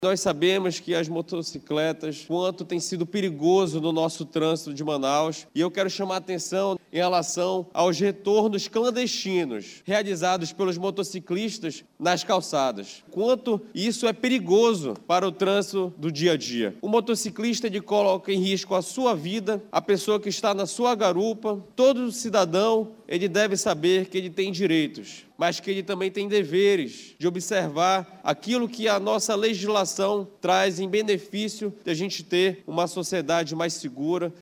O vereador Marco Castilhos, do União Brasil, usou a tribuna da Casa Legislativa, nessa terça-feira 06/04, para cobrar fiscalização mais severa aos motociclistas que desrespeitam as Leis de Trânsito na cidade.